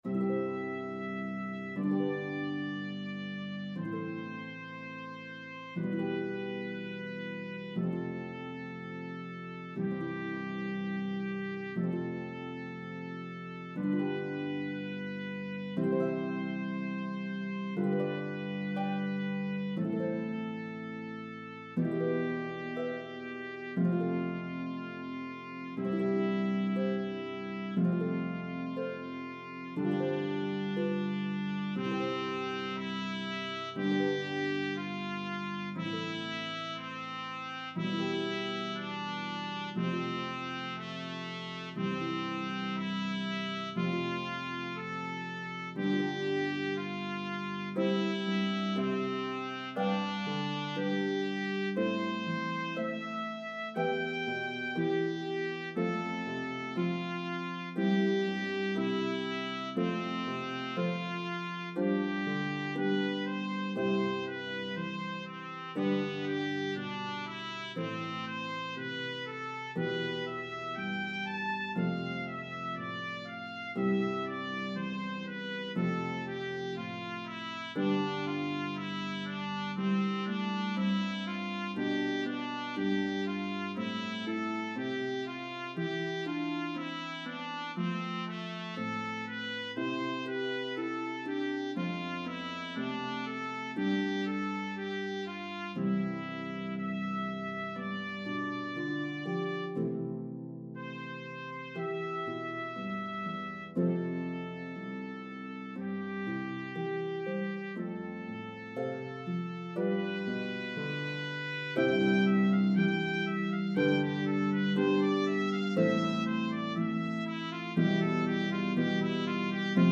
Harp and Trumpet in B-flat version